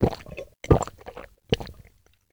inv_softdrink.ogg